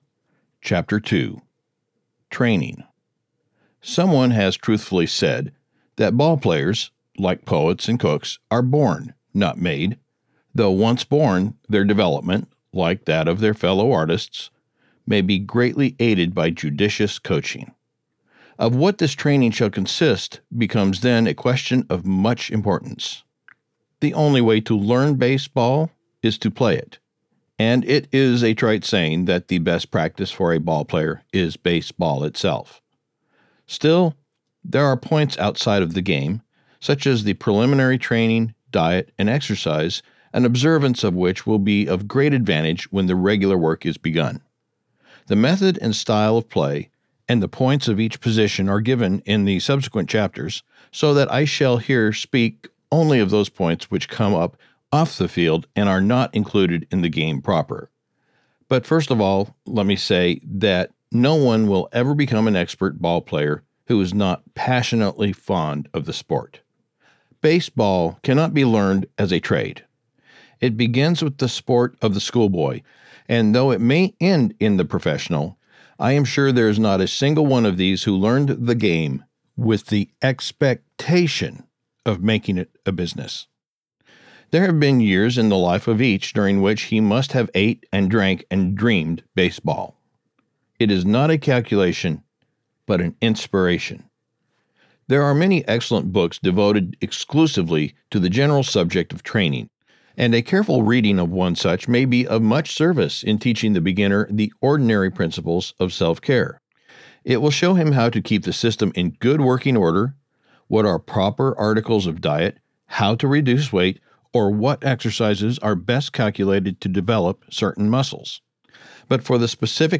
Audiobook production Copyright 2025, by GreatLand Media